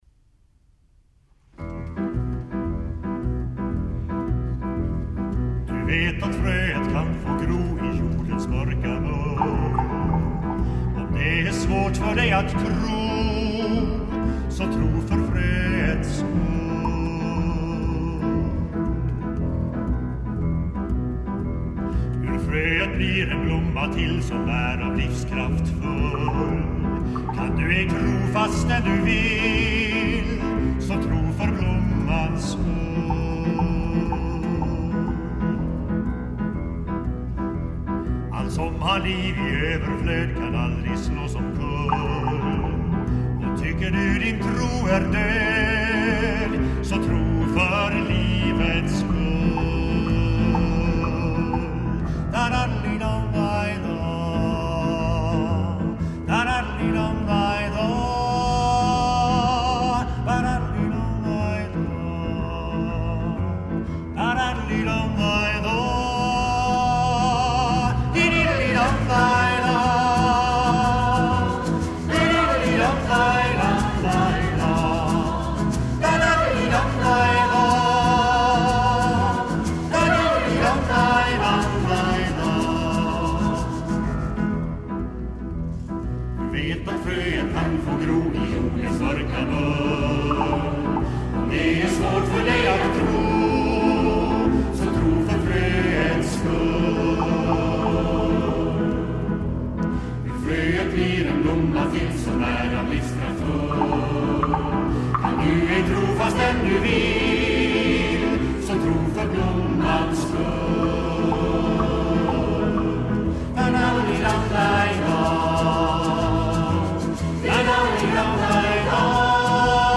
”Anslaget är lätt, tonen ljus